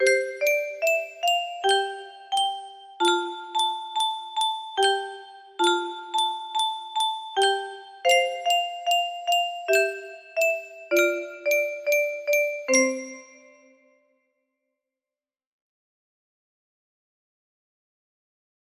Alle Maine Entchen music box melody